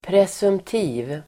Uttal: [presumt'i:v]